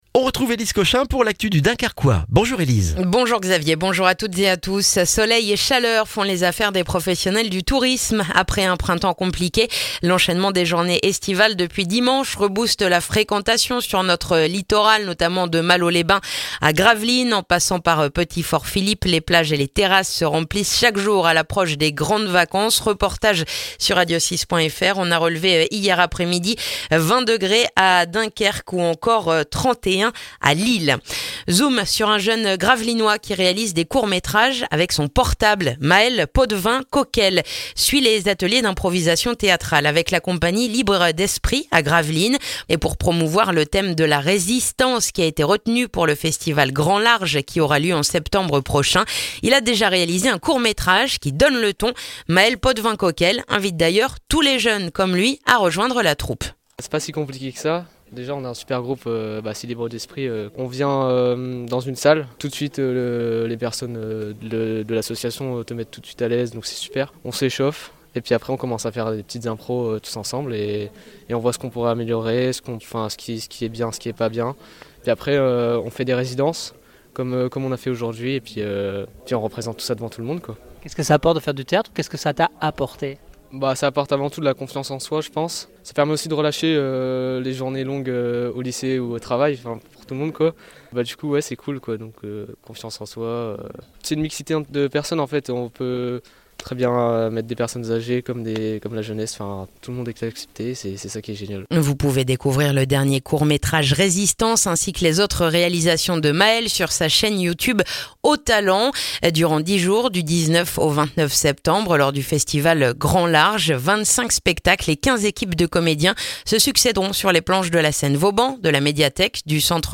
Le journal du jeudi 27 juin dans le dunkerquois